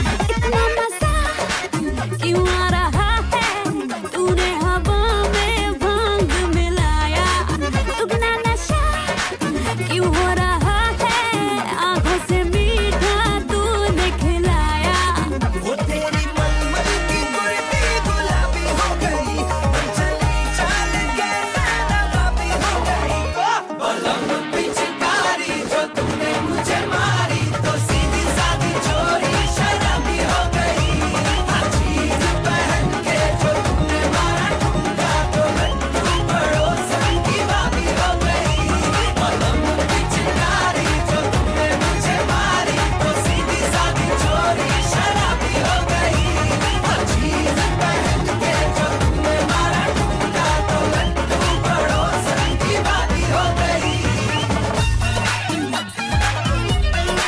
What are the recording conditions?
Holi at Barry Curtis Park- Auckland